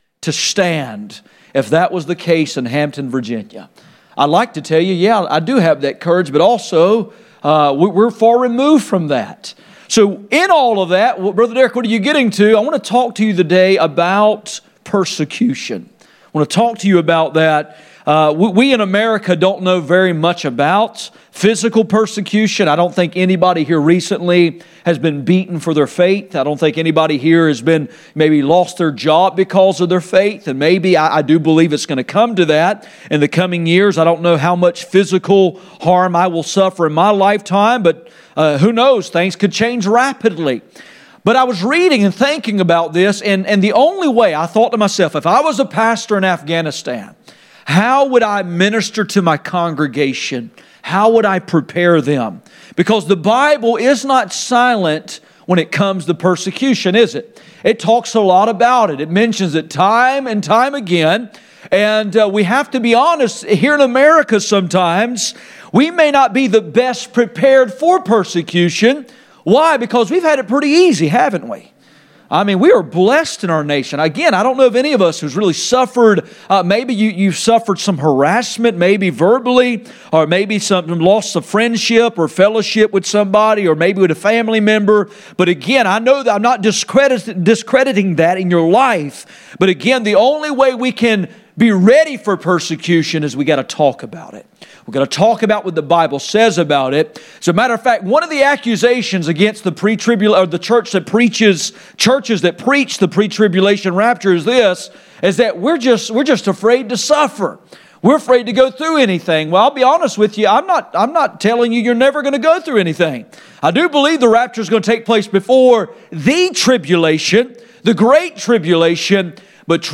None Passage: Matthew 5:10-12 Service Type: Sunday Morning %todo_render% « The results of returning to the Lord Persecution